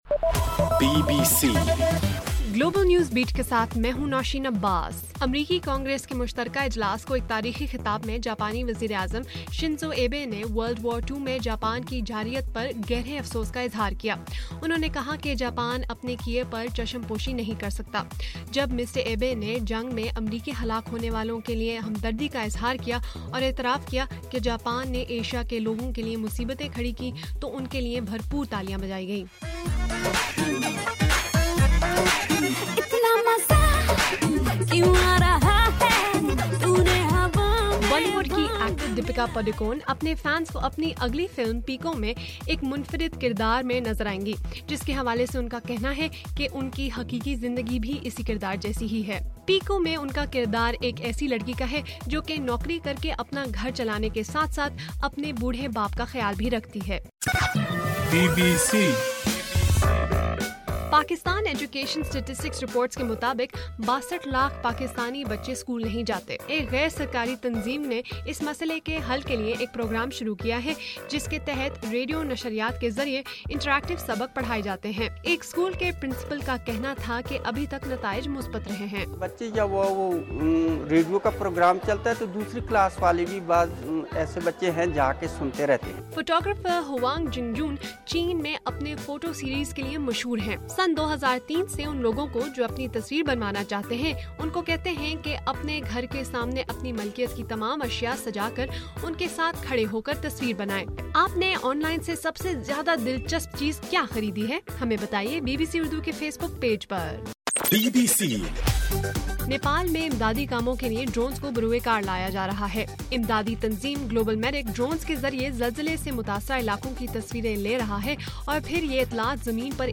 اپریل 29: رات 11بجے کا گلوبل نیوز بیٹ بُلیٹن